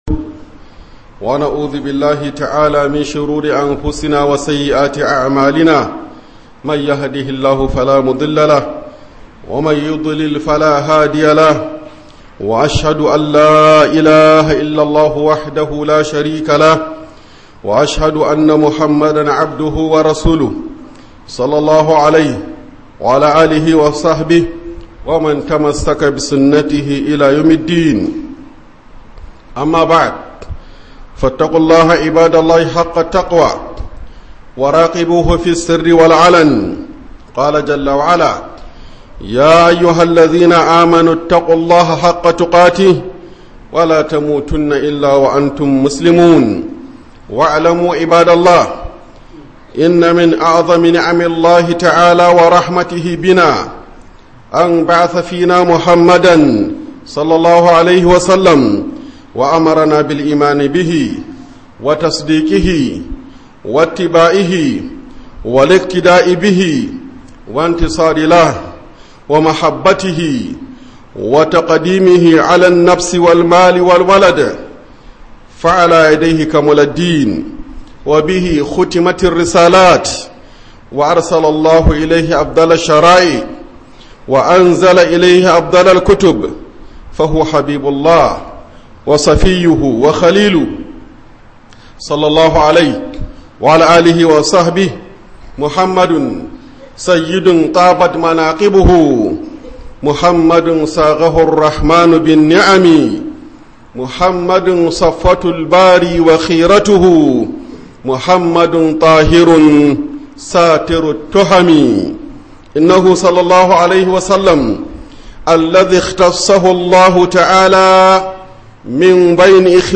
KHUDUBAN JUMMA'A